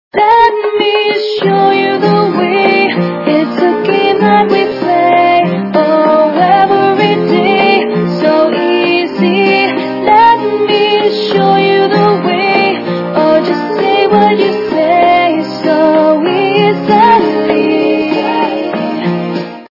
» Звуки » Из фильмов и телепередач » Из рекламы